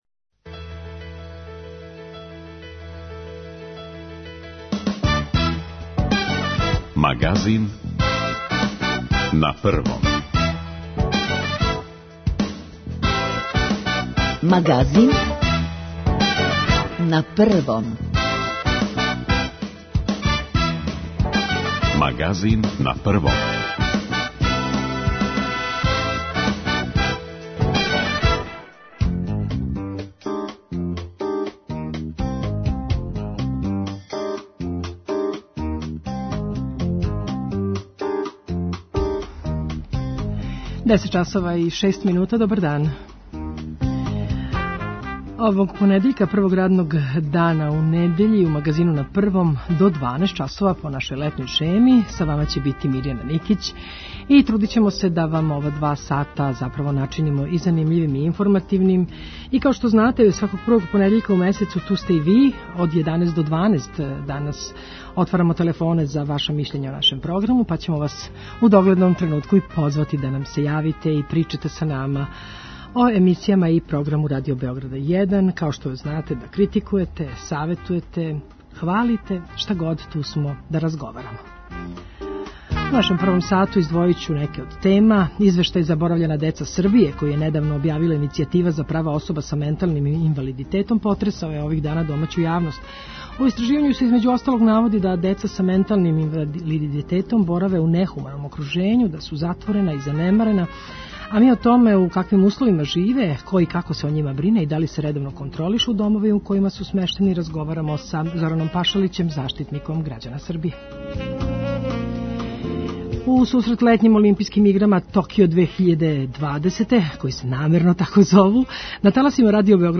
У истраживању се између осталог наводи да деца са менталним инвалидитетом бораве у нехуманом окружењу, да су затворена и занемарена. О томе у каквим условима живе, ко и како се о њима брине и да ли се редовно контролишу домови у којима су смештени, разговарамо са Зораном Пашалићем, заштитиником грађана Србије.
Као и сваког првог понедељка у месецу, и данас позивамо слушаоце да нам се јаве и кажу своје мишљење о емисијама и програму Радио Београда 1.